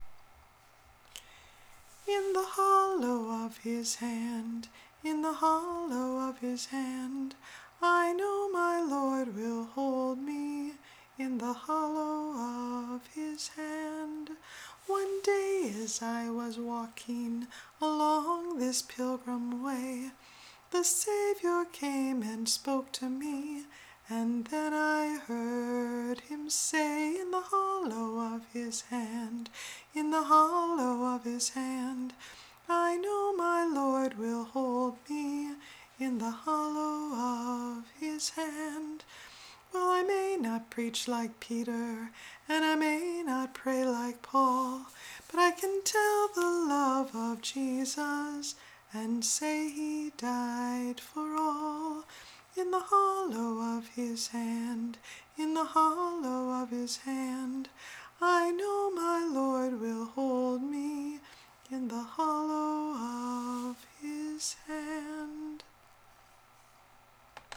The words and melody are from my memory.